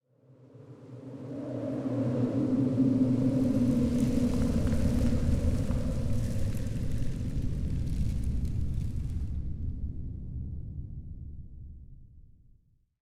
328d67128d Divergent / mods / Soundscape Overhaul / gamedata / sounds / ambient / soundscape / wind / housewind11.ogg 322 KiB (Stored with Git LFS) Raw History
housewind11.ogg